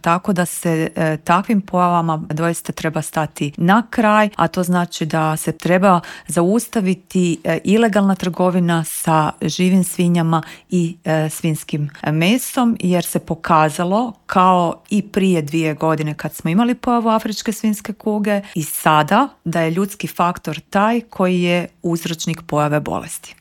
U popodnevnim satima sastaje se i saborski Odbor za poljoprivredu čija je predsjednica Marijana Petir gostovala u Intervjuu Media servisa: